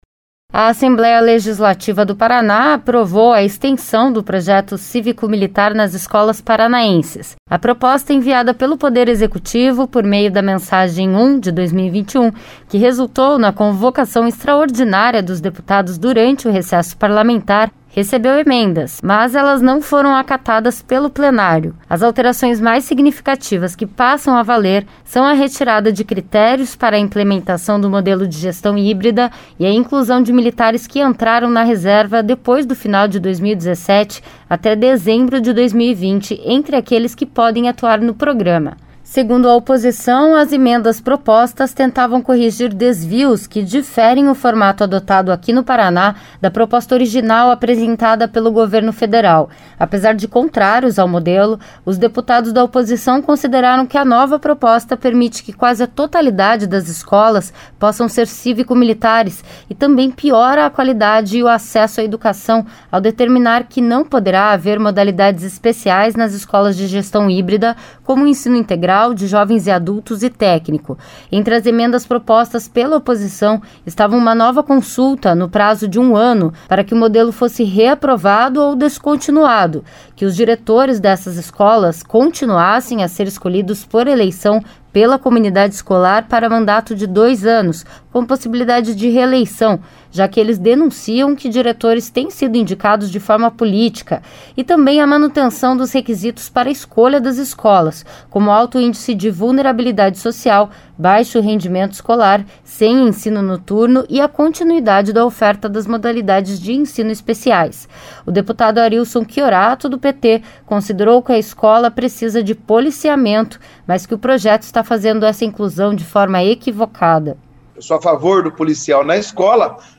O deputado Arilson Chiorato (PT)  considerou que a escola precisa de policiamento mas que o projeto está fazendo essa inclusão de forma equivocada.
O deputado Evandro Araújo (PSC) disse que seu posicionamento não teve cunho ideológico e que votou a favor por acreditar que a educação precisa de investimento.